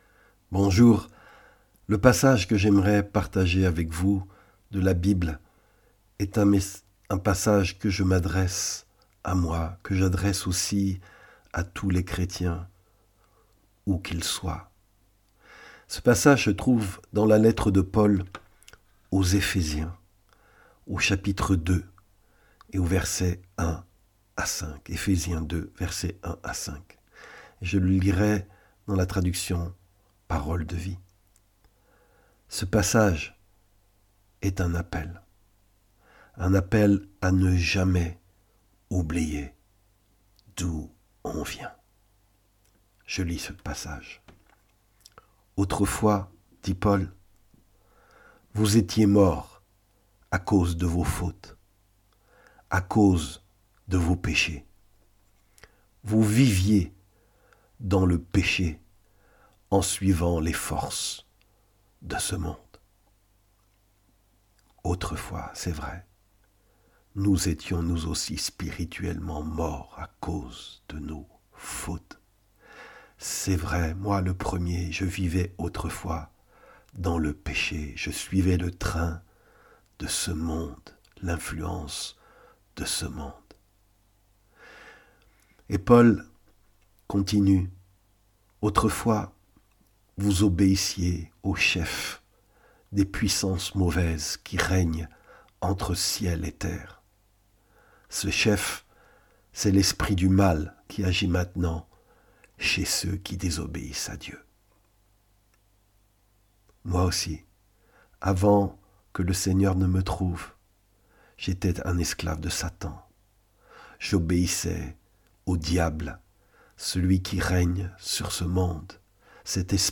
Enseignement biblique : L'importance de faire mémoire